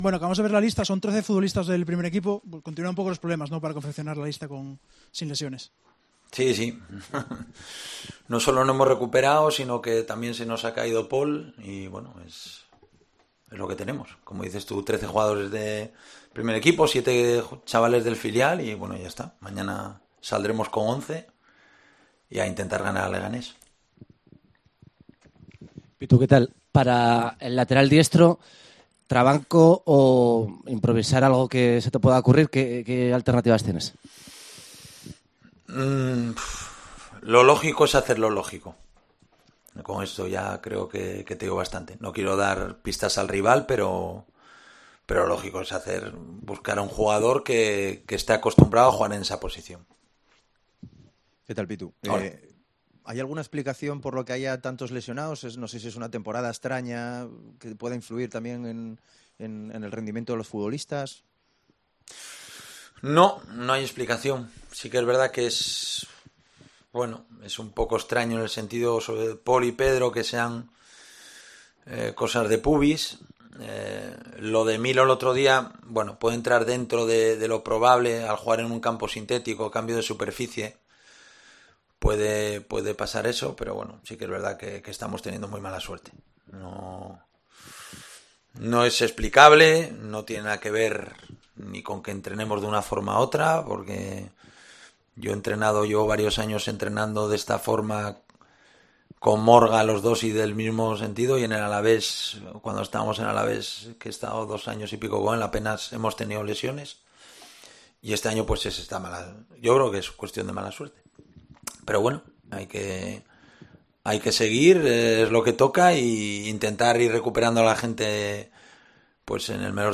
Rueda de prensa Abelardo (previa Leganés)